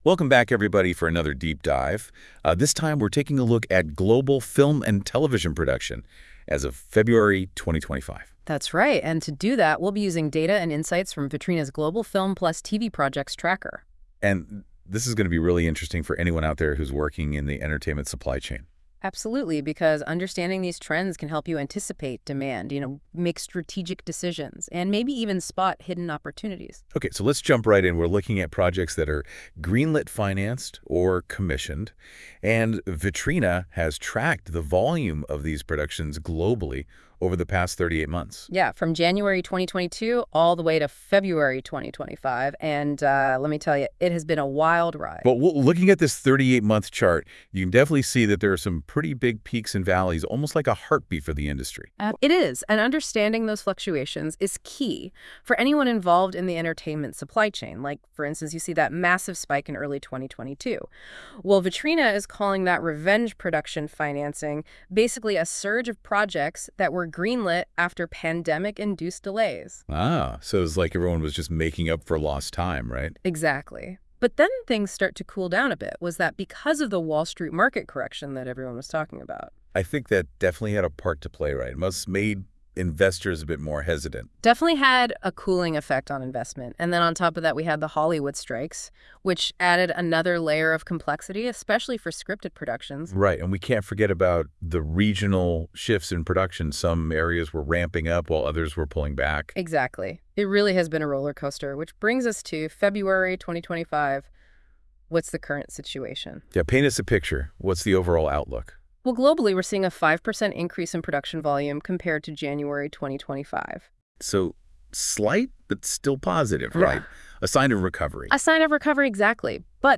The audio podcast was generated with Deep Dive and reviewed by our team.